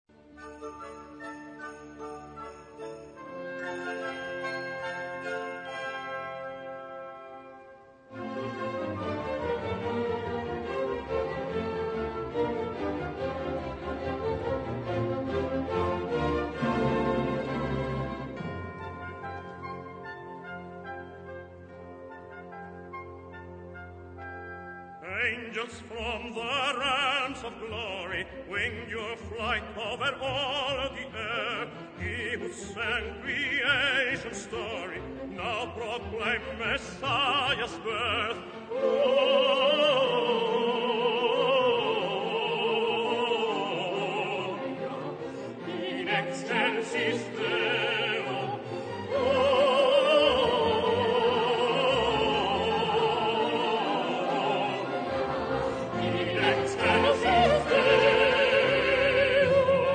key: F-major